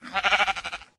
mob / sheep / say1.ogg